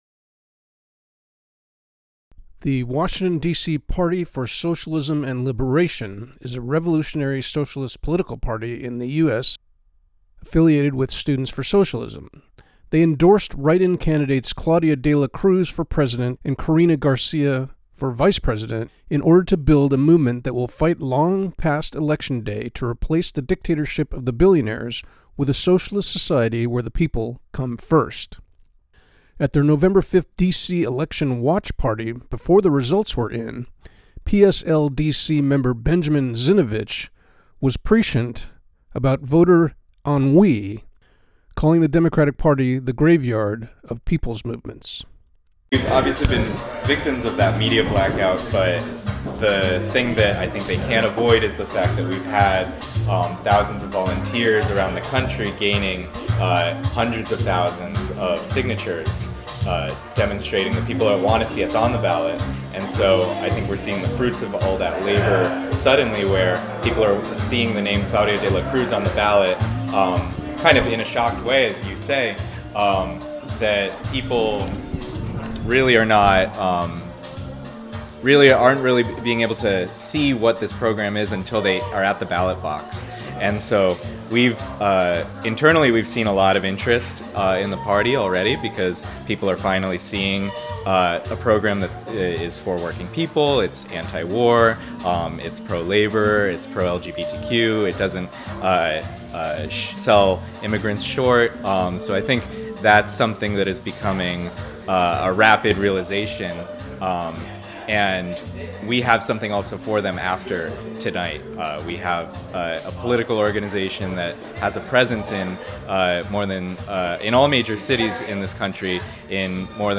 Interview with member of DC Party for Socialism and Liberation